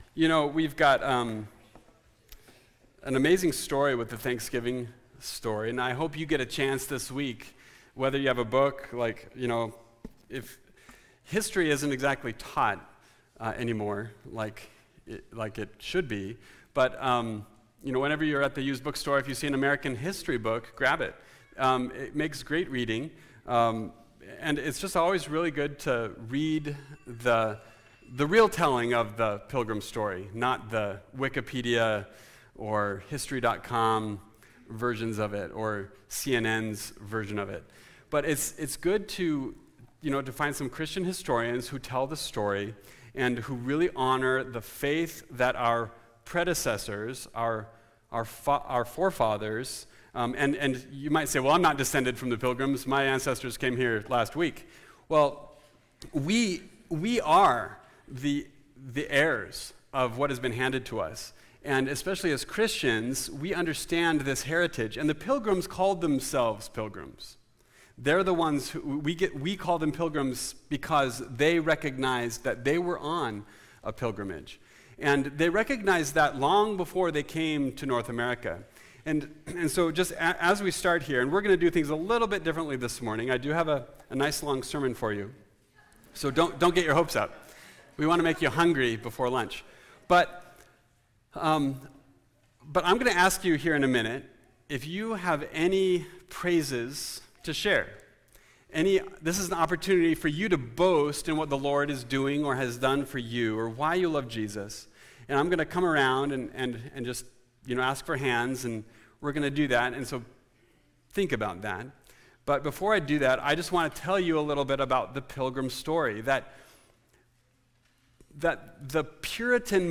Series: Topical Message